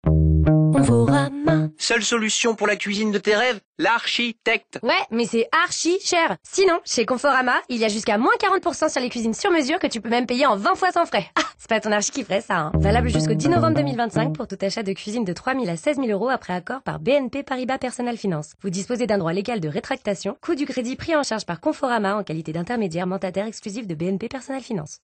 Le message diffusé empruntait un ton humoristique sans volonté de créer une quelconque forme de dévalorisation de cette profession.
spot-radio-CONFORAMA.mp3